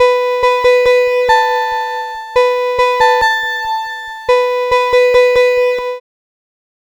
Cheese Lix Synth 140-B.wav